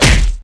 SFX monster_hit_common.wav